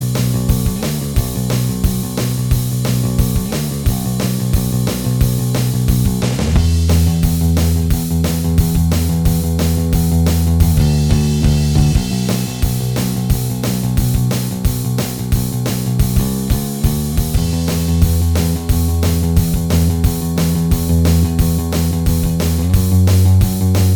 Minus Guitars Rock 3:59 Buy £1.50